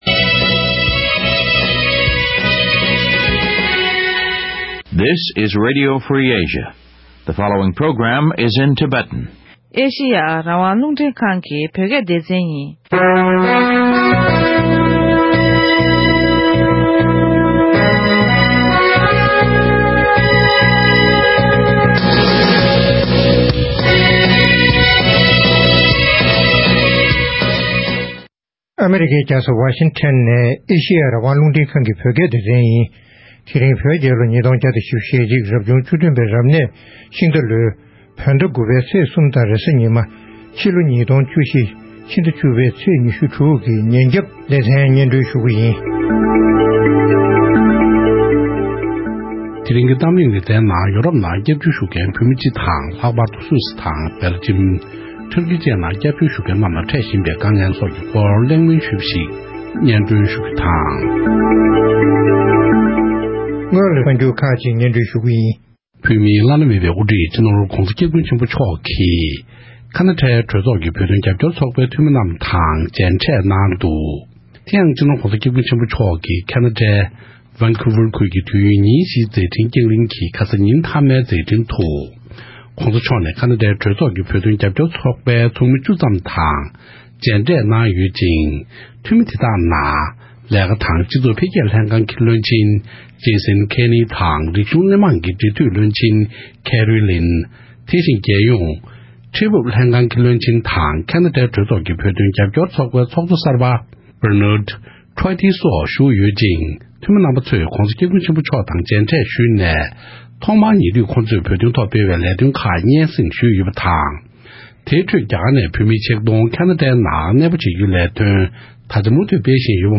ཐེངས་འདིའི་གཏམ་གླེང་ཞལ་པར་གྱི་ལེ་ཚན